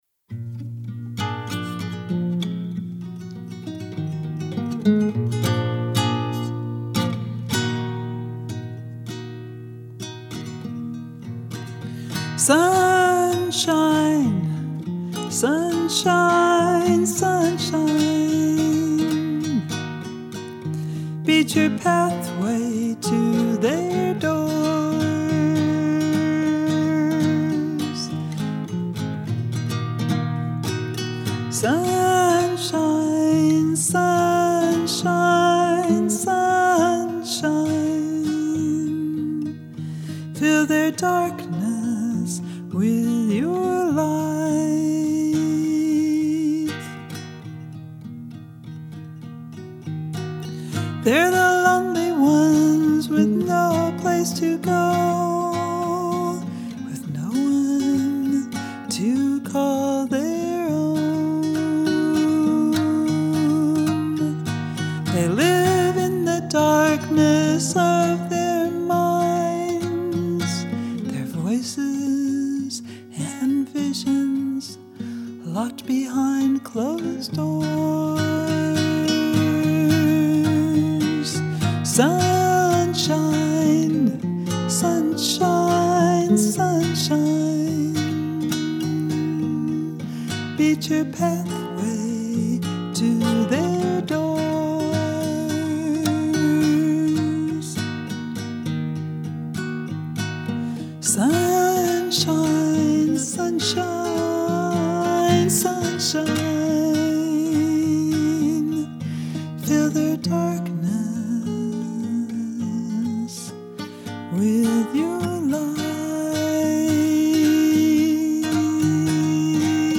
sings this heartfelt prayer to Sunshine asking for help–to bring light to the darkness of those who are lonely and in despair.